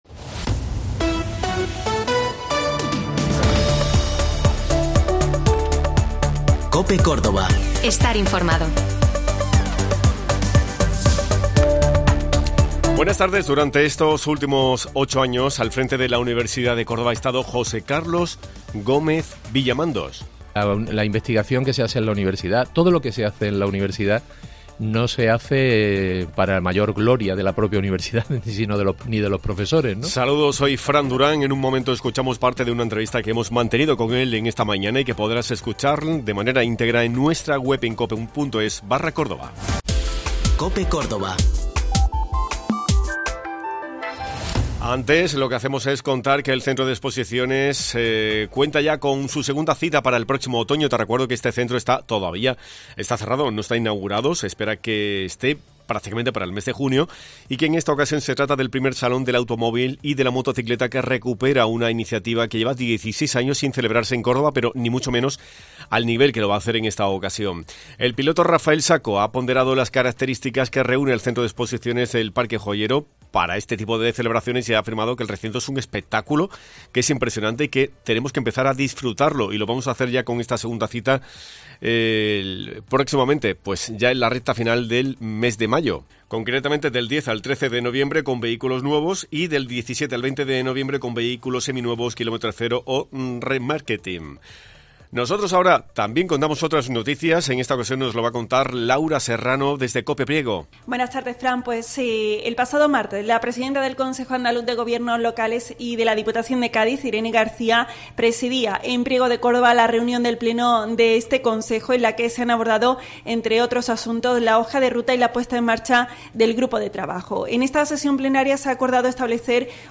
Aquí tienes un resumen de su entrevista.